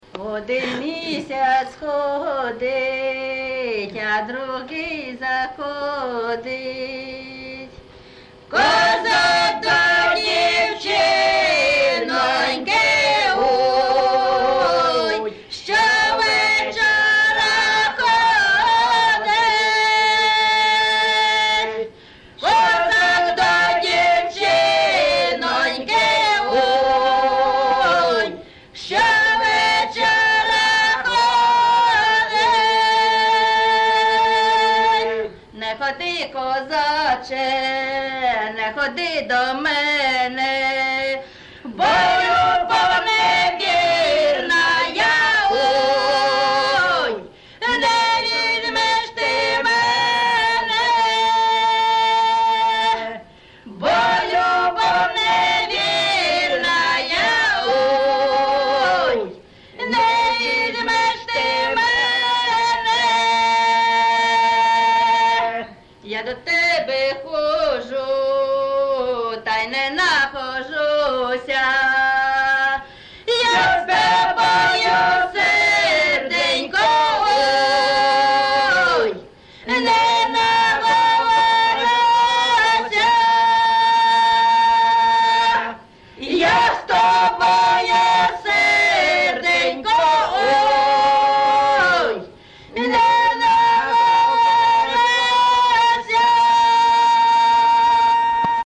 ЖанрПісні з особистого та родинного життя
Місце записус. Яблунівка, Костянтинівський (Краматорський) район, Донецька обл., Україна, Слобожанщина